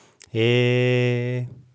Vocale în format .wav - Vorbitorul #22